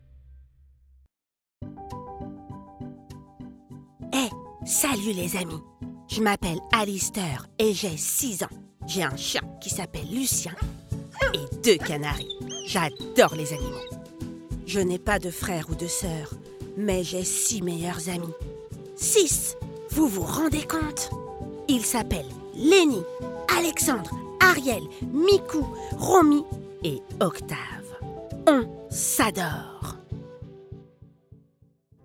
Une histoire sur l'amitié, lu par plusieurs comédiens, illustré de musiques et ambiances sonores pour les plus petits !